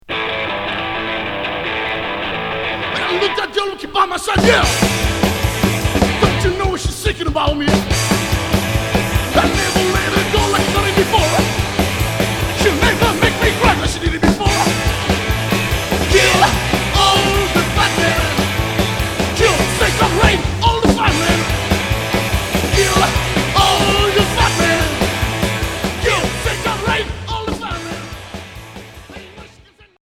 Psychobilly